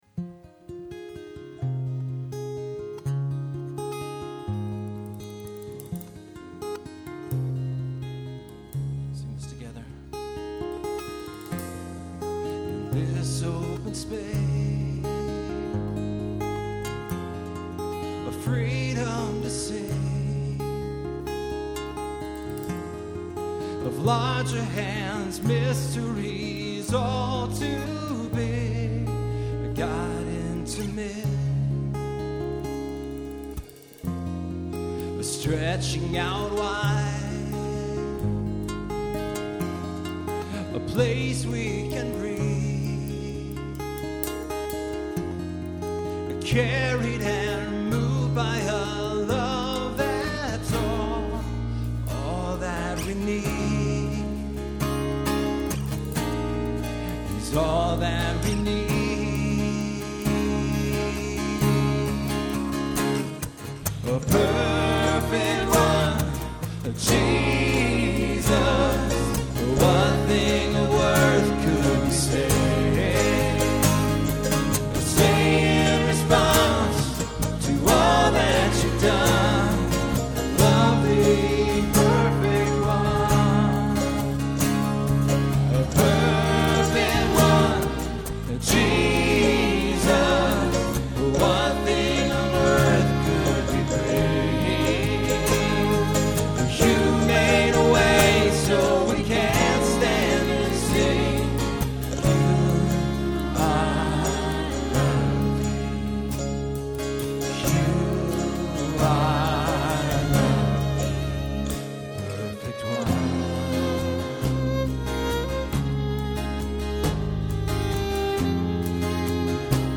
Performed live at Terra Nova - Troy on 11/9/08.